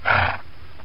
breathe3.ogg